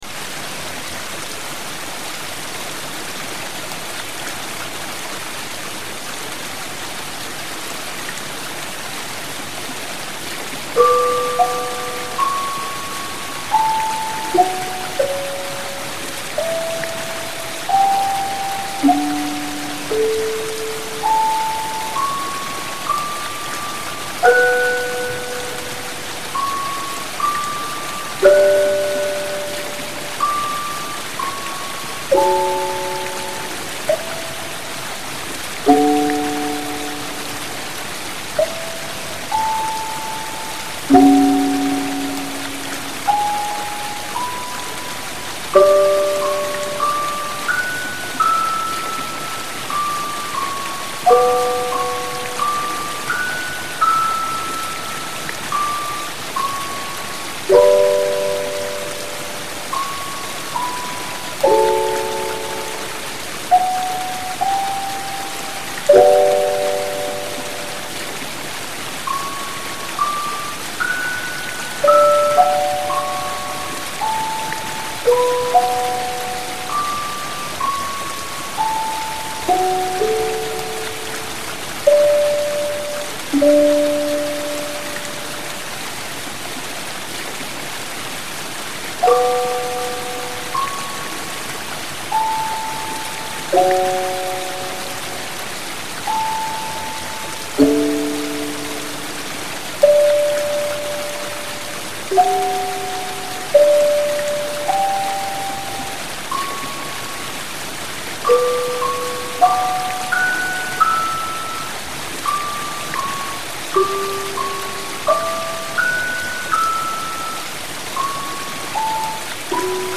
This was made on 30 of august, so we needed to create a song in one day, there was no time to compose so this song its only simple improvisation made by me and my friend put the sound of the rain.